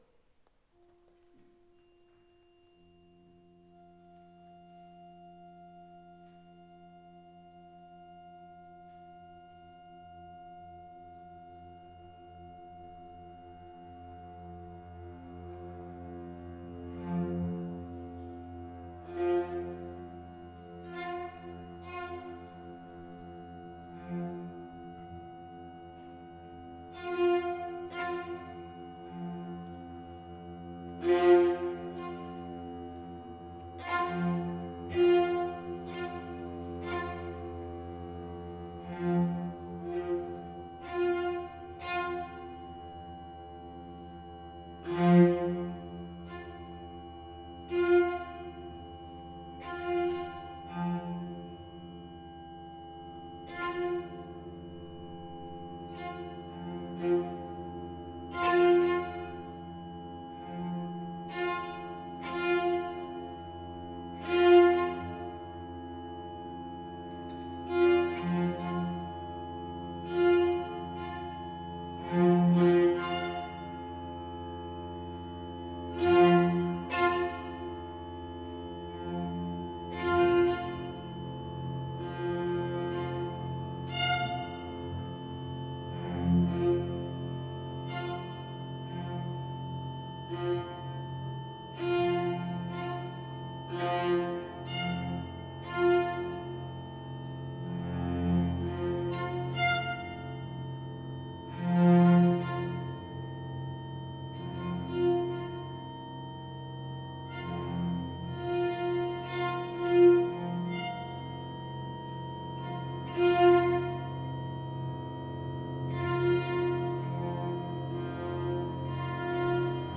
Tuning: 12edo